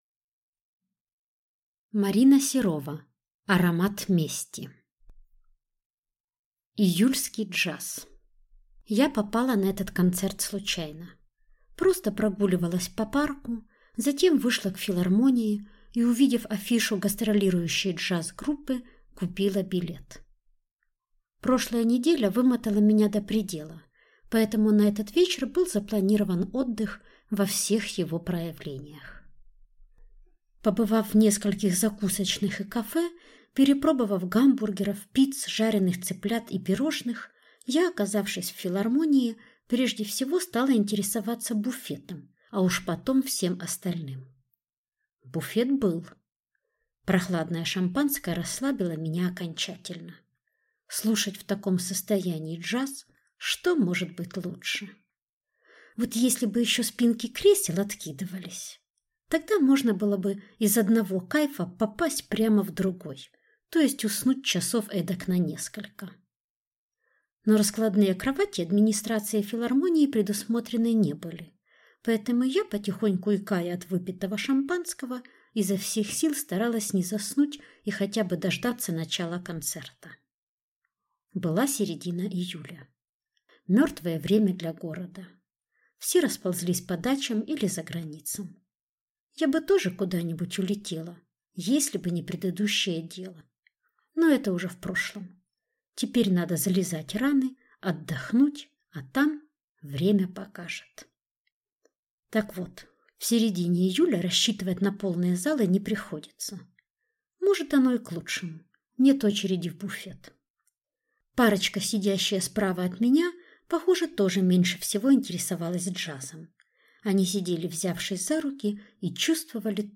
Аудиокнига Аромат мести | Библиотека аудиокниг